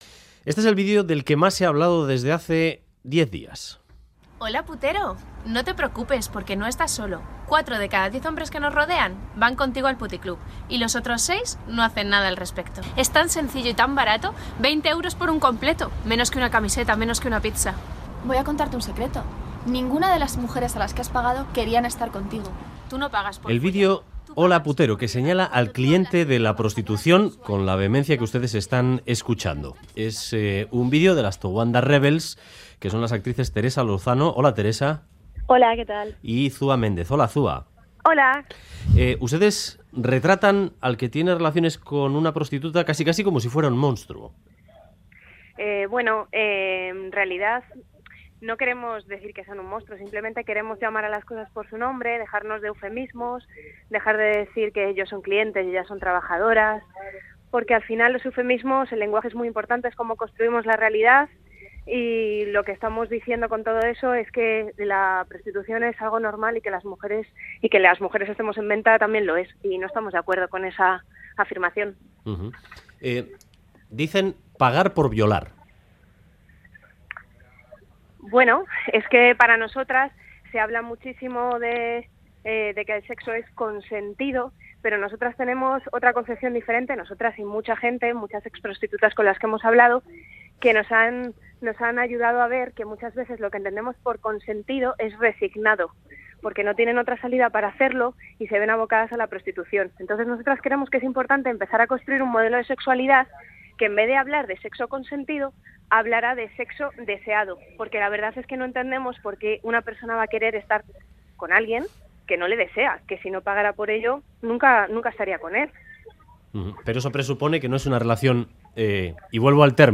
Con ellas hemos hablado sobre prostitución en 'Boulevard'.